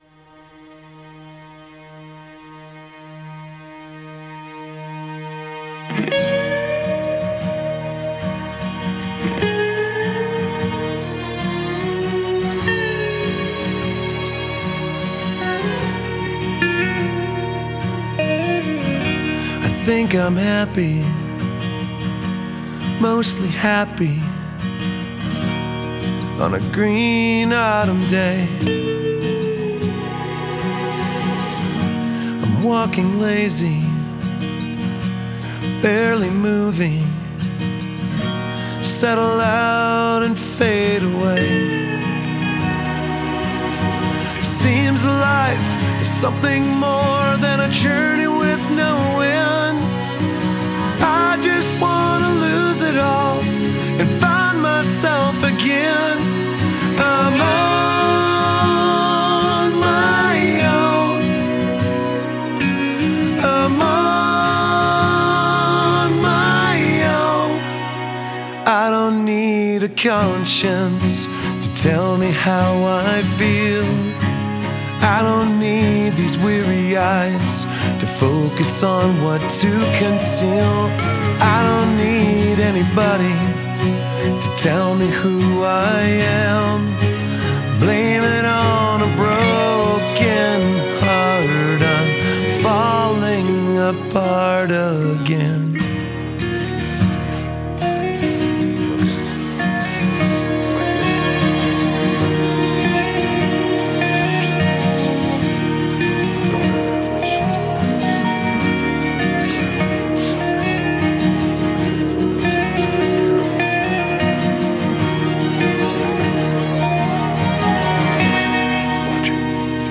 راک
متال